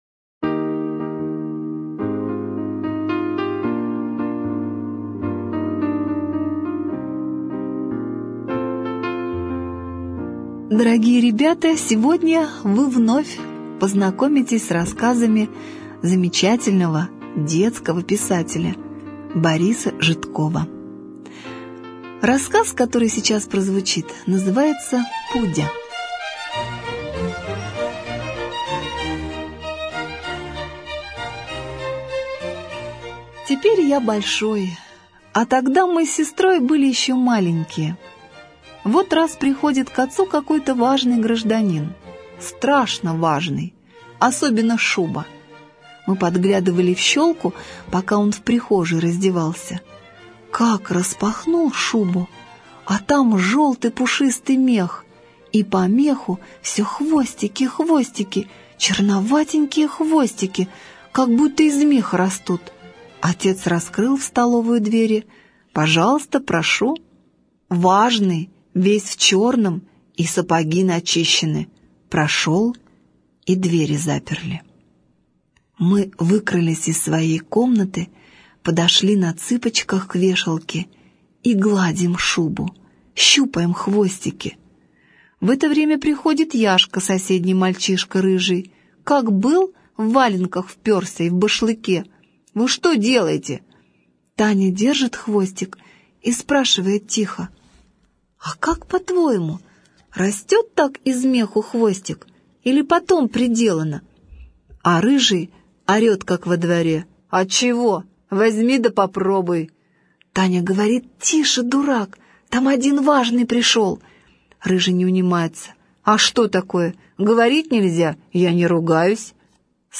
Пудя - аудио рассказ Житкова Б.С. Однажды к нам в гости пришел гость. Он снял шубу, а внутри был желтый пушистый мех с маленькими хвостиками.